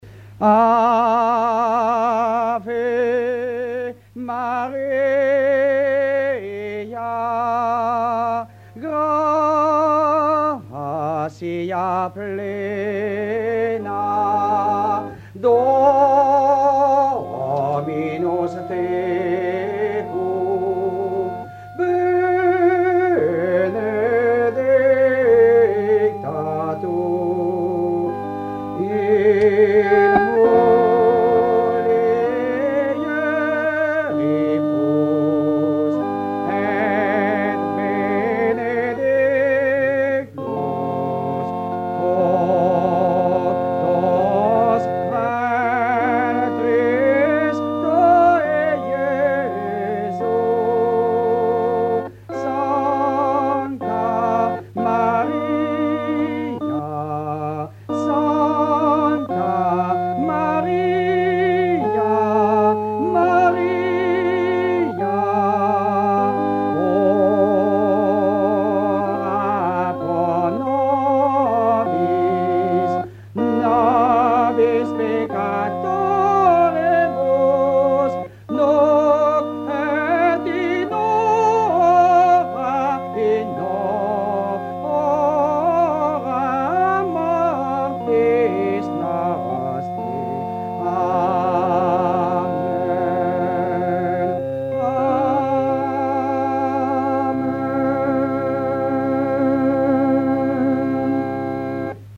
numérisation d'émissions
Pièce musicale inédite